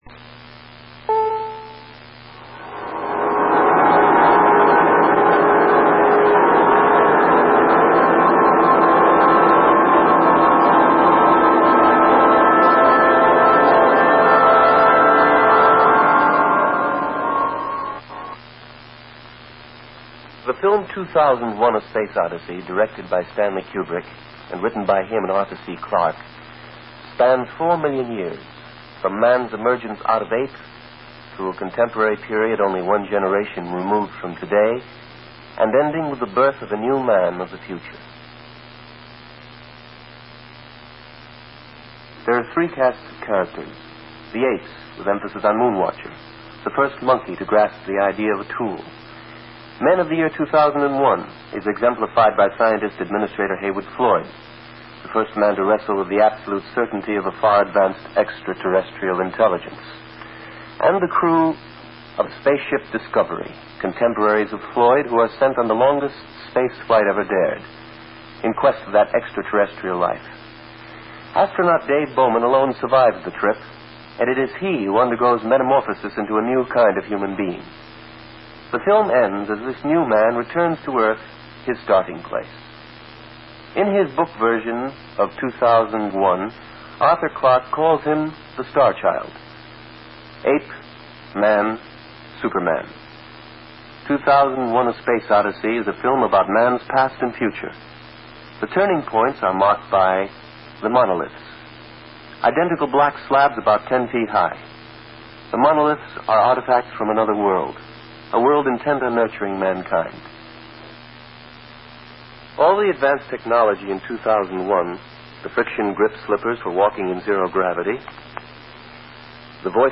Click here Recorded 30 years ago or so. The 'bong' at the beginning suggests it was on WCBS radio.
The narrator says the model was 'this big' and mentions video clips.